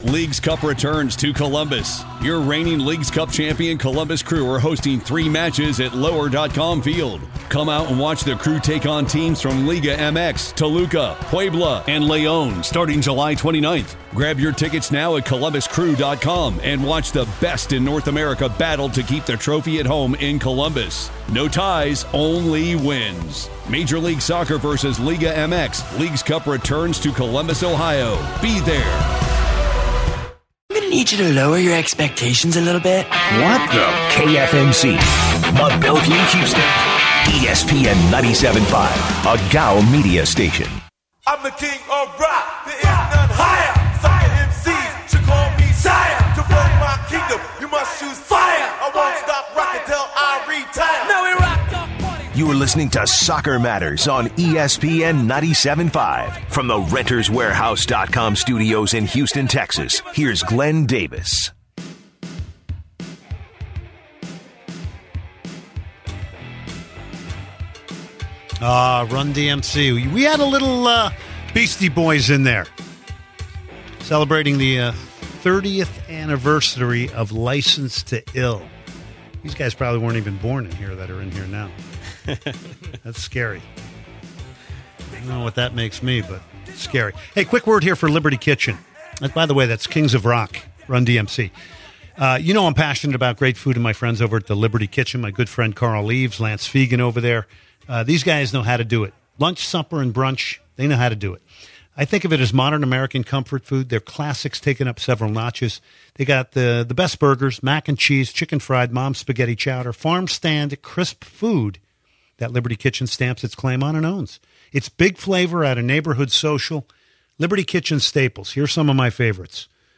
Socccer Matters kicks off with the Roundtable talk on the USMNT and the future Jurgen Klinsmann holds with the National team. Will the USA Soccer Federation sack Jurgen or will they hold out for a better results?